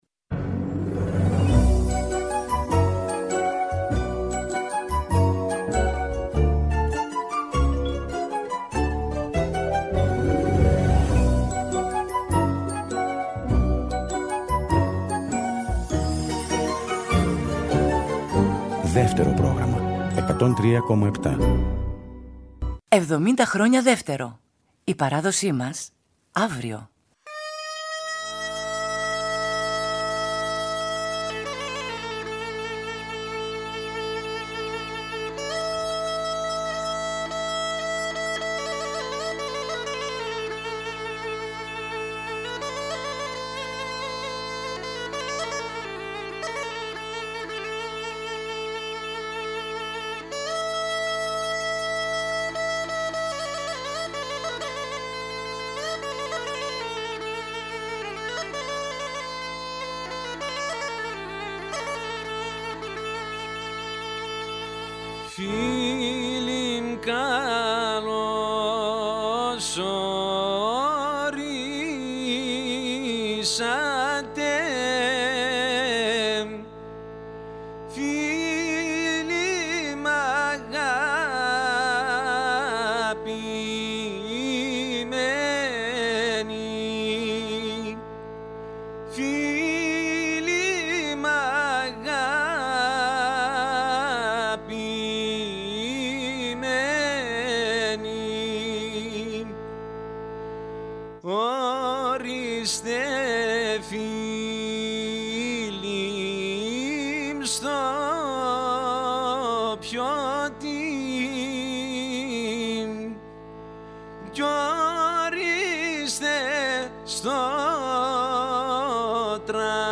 Ένας κύκλος 15 εκπομπών, που θα μεταδίδονται από τις 2 Μαΐου και κάθε μέρα έως τις 15 Μαΐου, στις 13.00, με ζωντανές ηχογραφήσεις σε Αθήνα και Θεσσαλονίκη με συγκροτήματα και μουσικές ομάδες από όλη την Ελλάδα, αλλά μουσικές συμπράξεις μόνο για τα γενέθλια του Δευτέρου Προγράμματος.
γκάιντα, τραγούδι
καβάλι, τραγούδι
ακορντεόν
νταούλι